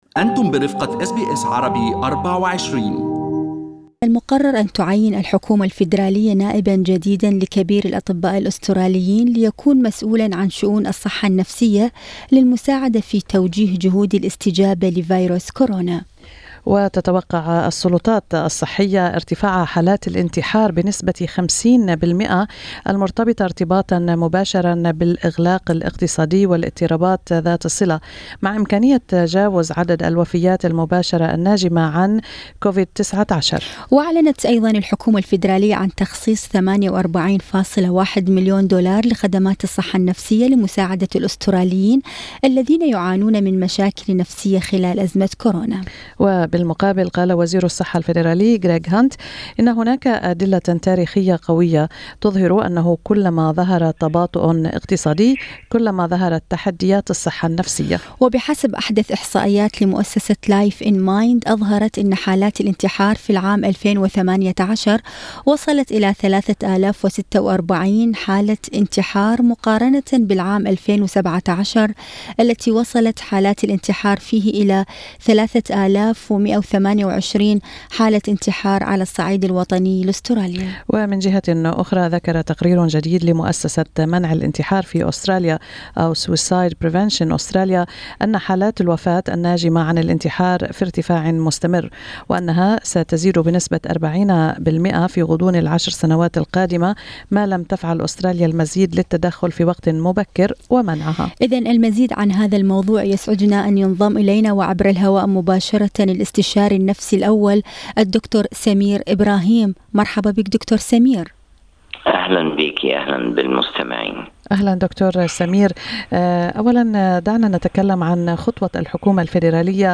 لقاءِ مع برنامج استراليا اليوم